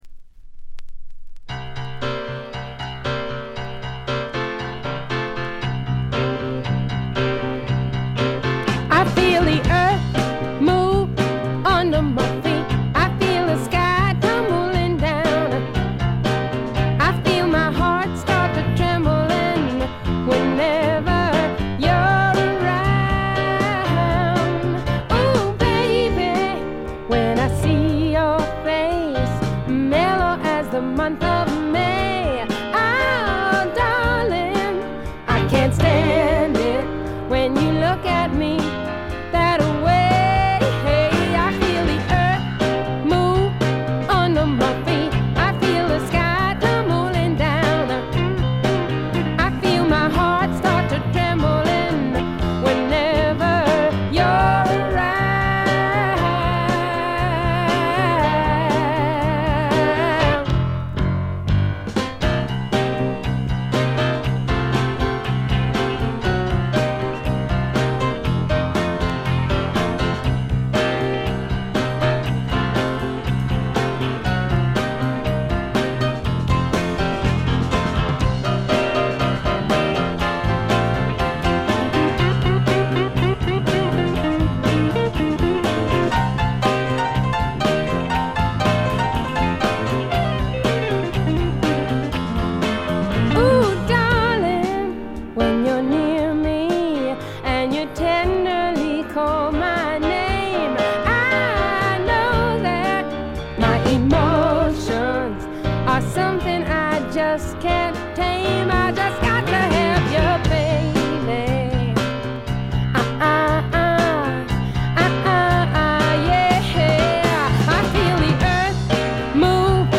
試聴曲は現品からの取り込み音源です。
シンセサイザー、ギター、ピアノ、キーボード、ボーカル、バックグラウンド・ボーカル